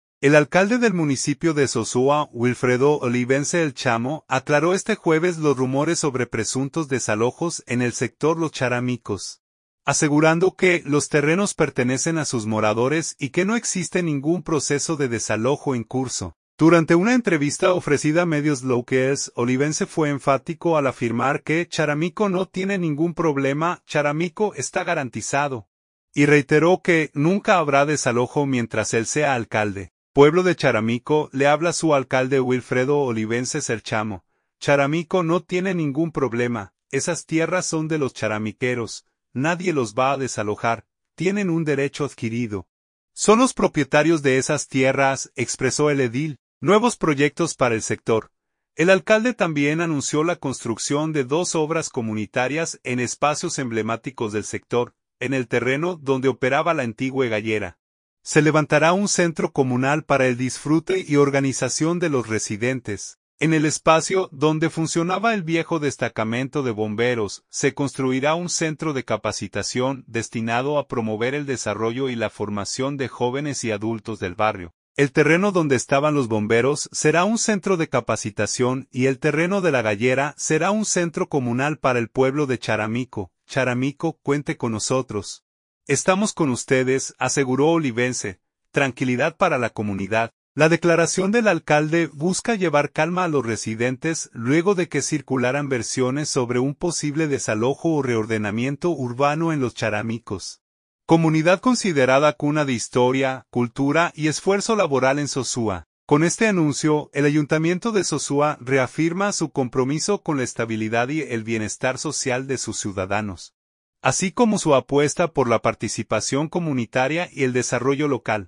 Durante una entrevista ofrecida a medios locales, Olivense fue enfático al afirmar que “Charamico no tiene ningún problema, Charamico está garantizado”, y reiteró que “nunca habrá desalojo mientras él sea alcalde”.